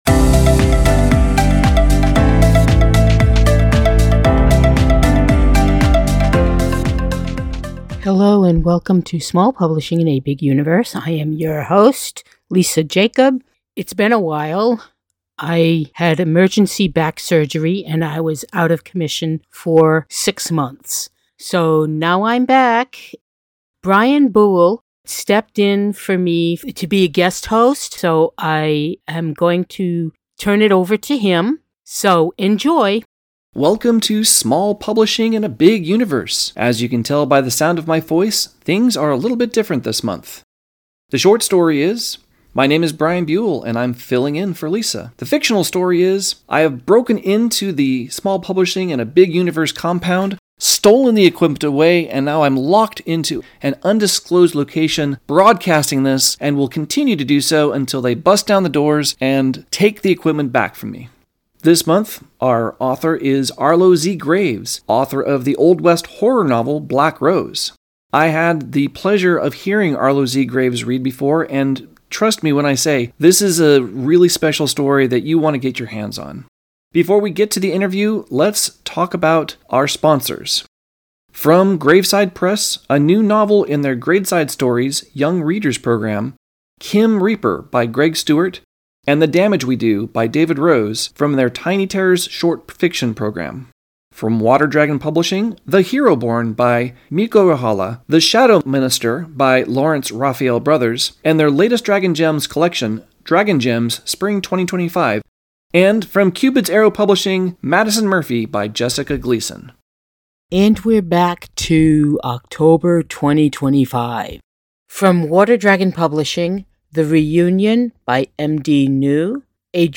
Our goal is to bring you interviews and discussions about a variety of publishing- and writing-related topics.